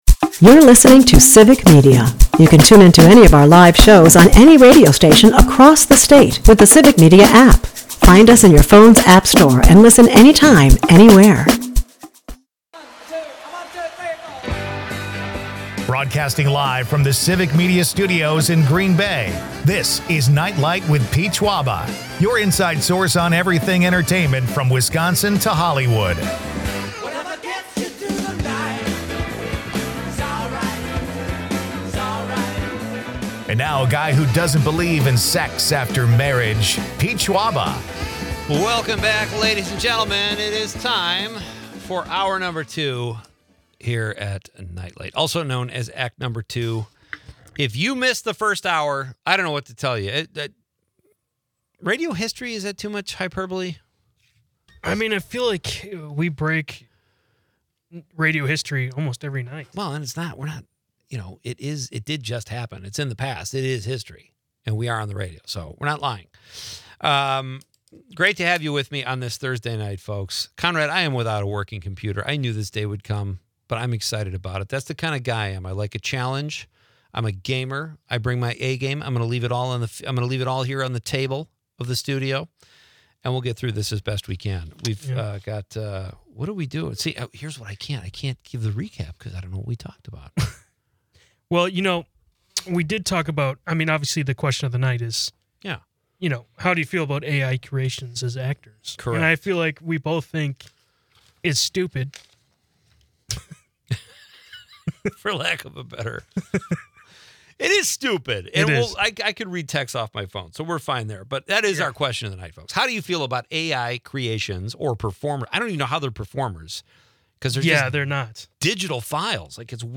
Listeners chime in on AI with skepticism, as the episode wraps with a nod to local haunts and upcoming events.